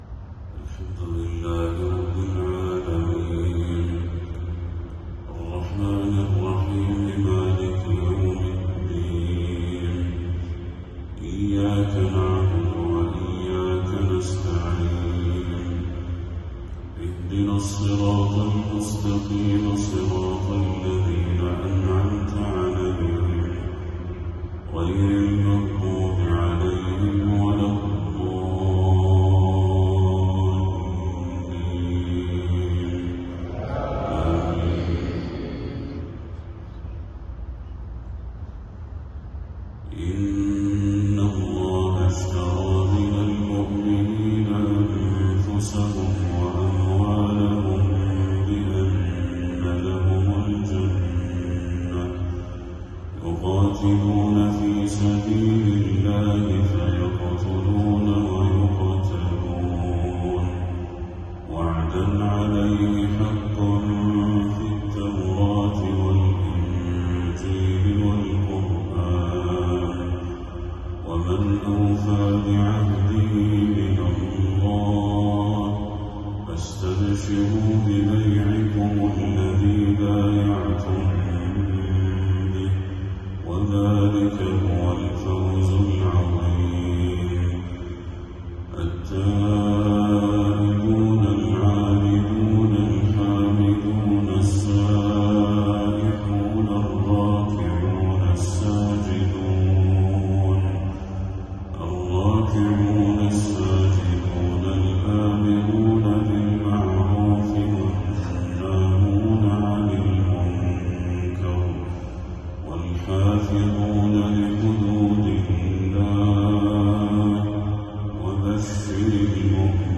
تلاوة خاشعة من سورة التوبة للشيخ بدر التركي | فجر 21 صفر 1446هـ > 1446هـ > تلاوات الشيخ بدر التركي > المزيد - تلاوات الحرمين